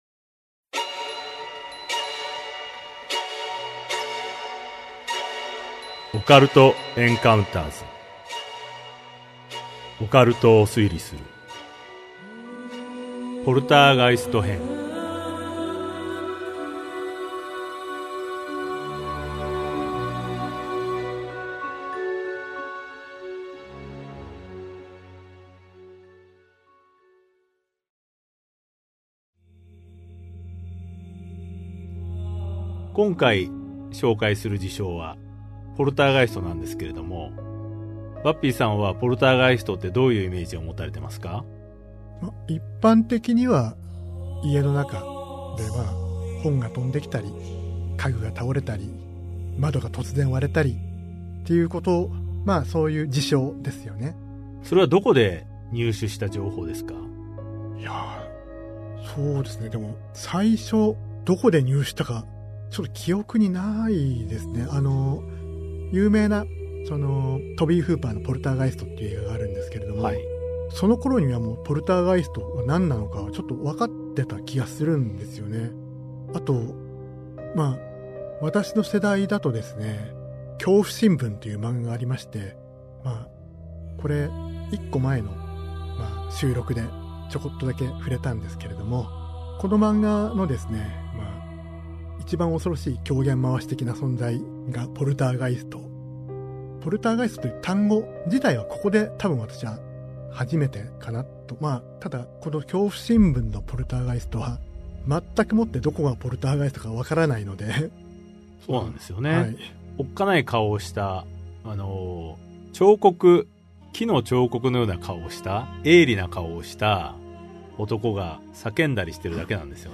[オーディオブック] オカルト・エンカウンターズ オカルトを推理する Vol.06 ポルターガイスト編